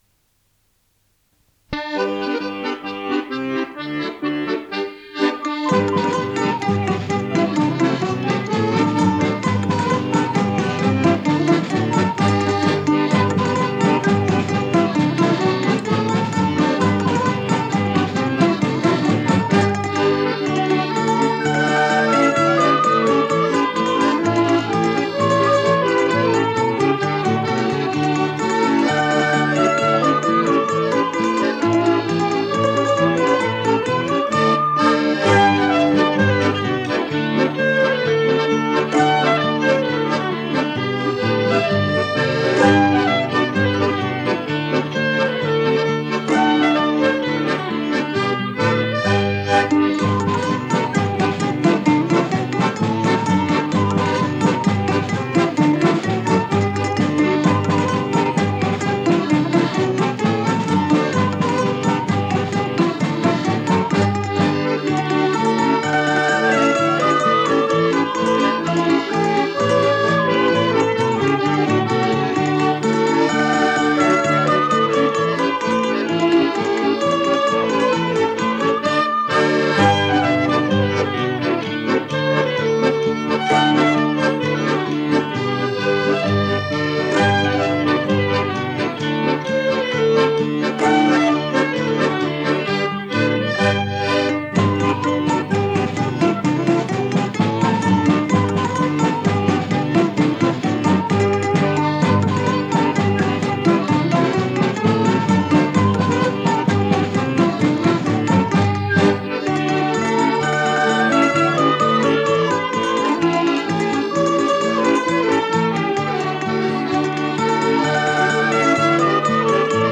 Polkapas
Kringpolka.mp3